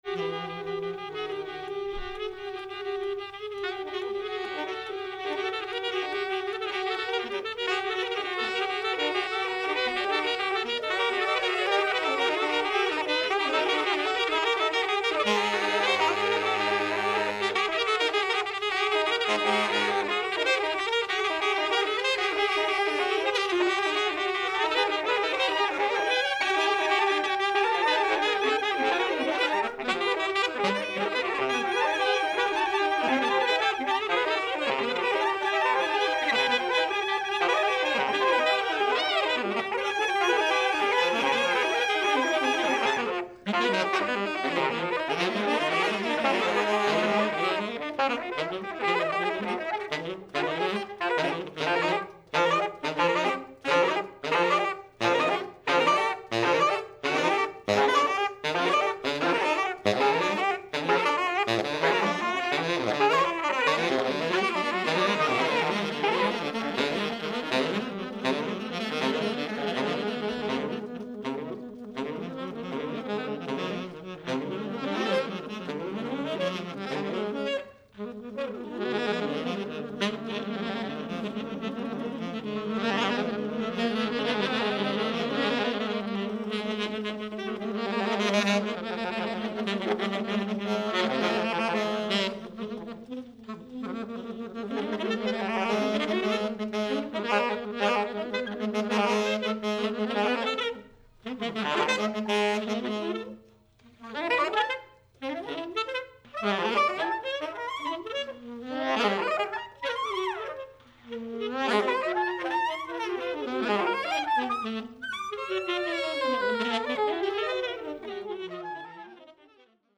Volume 1, Cafe OTO 9th July 2022